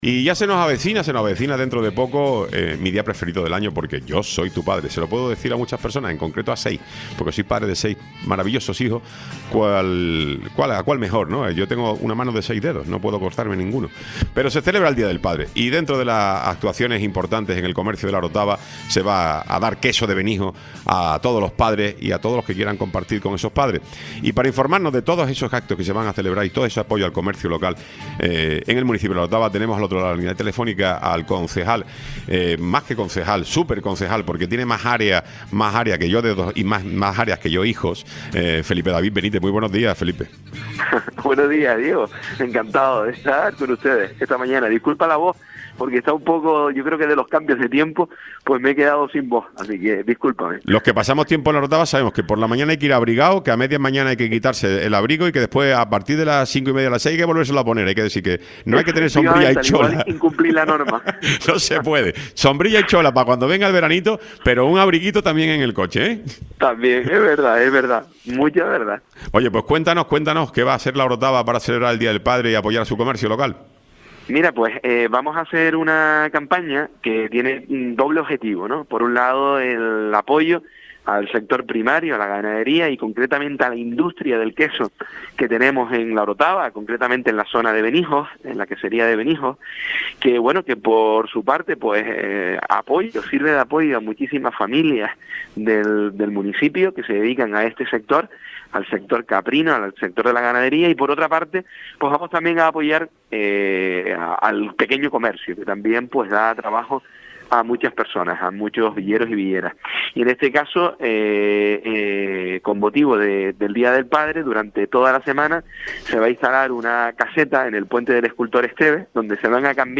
Intervención de Felipe David Benítez, concejal de Comercio del Ayto. de La Orotava